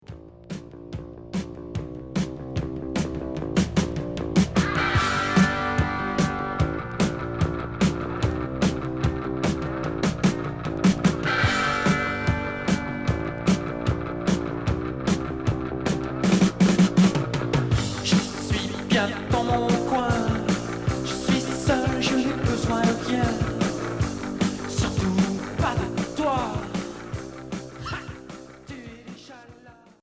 Punk rock Troisième 45t retour à l'accueil